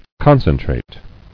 [con·cen·trate]